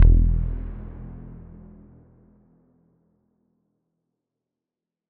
Bass_E_03.wav